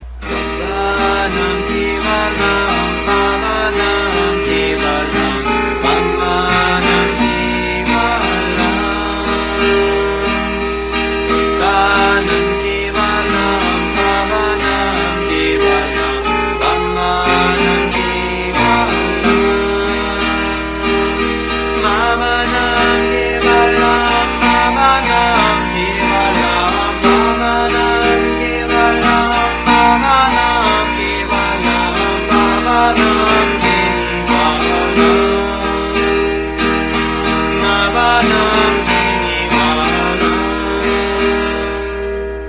Kapo 3rd fret